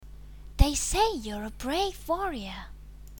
Englische Sprecher (f)
innocent / unschuldig